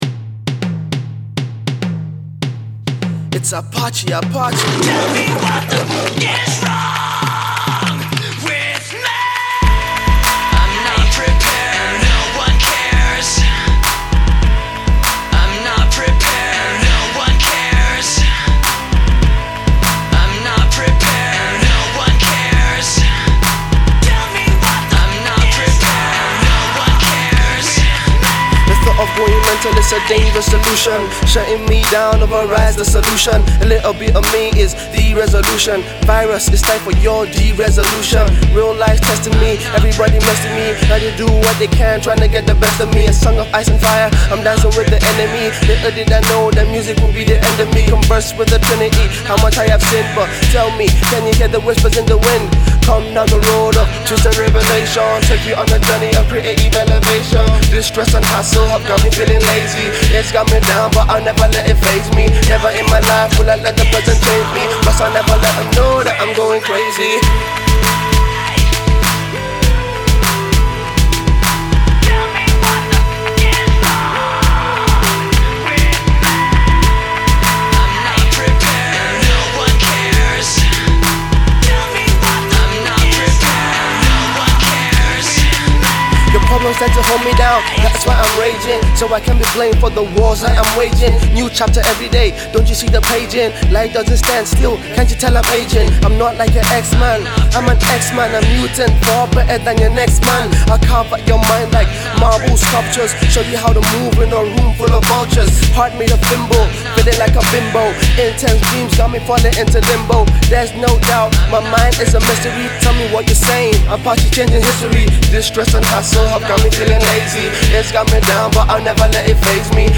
in one of his hoarse screaming croons